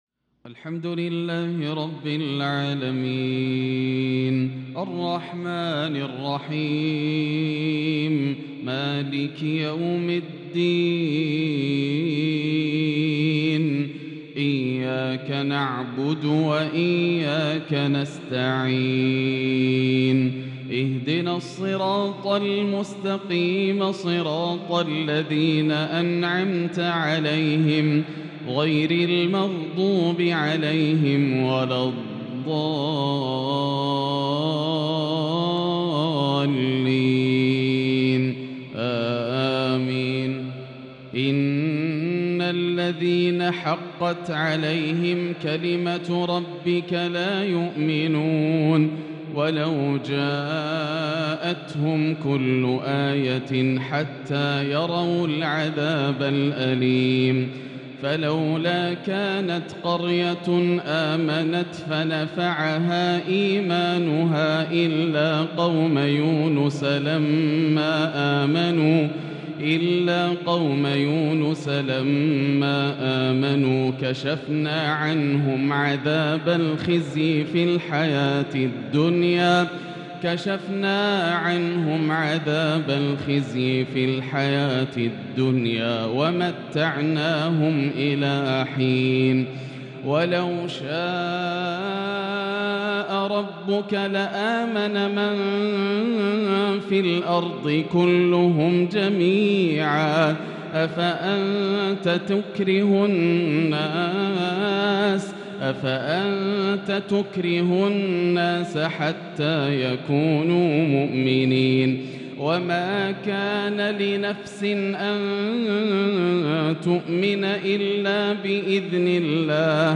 عشاء الأحد 6-6-1443هـ خواتيم سورة يونس | Isha prayer from Surah Younis 9-1-2022 > 1443 🕋 > الفروض - تلاوات الحرمين